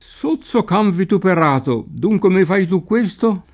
cane [k#ne] s. m. («animale») — tronc. in certe locuz.: can barbone; menare il can per l’aia; in certi proverbi: can che abbaia non morde; non svegliare il can che dorme; a can che lecca cenere non gli fidar farina; in certi nomi propri: via del Can Bianco, a Pistoia; nell’uso pop. o lett., anche in altri casi, particolarm. davanti alla specificaz. di una razza o di altre caratteristiche, oppure, in antico, davanti ad aggiunte ingiuriose: sozzo can vituperato, dunque mi fai tu questo?